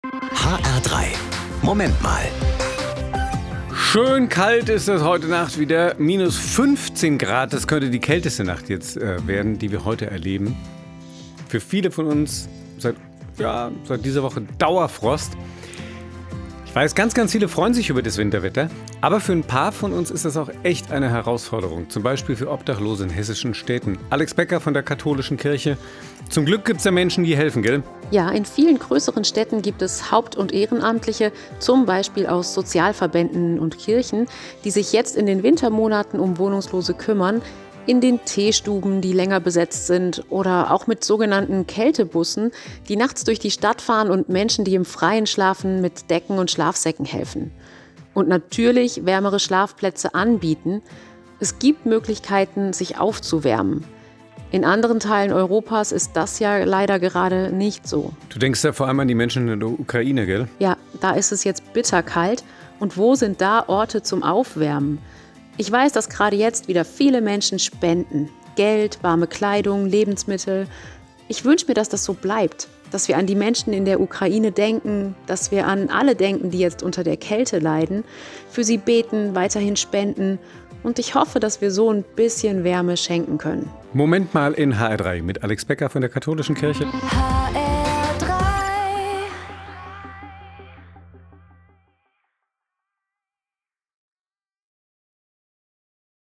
Katholische Pastoralreferentin Frankfurt